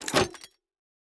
Minecraft Version Minecraft Version snapshot Latest Release | Latest Snapshot snapshot / assets / minecraft / sounds / block / vault / insert_fail.ogg Compare With Compare With Latest Release | Latest Snapshot
insert_fail.ogg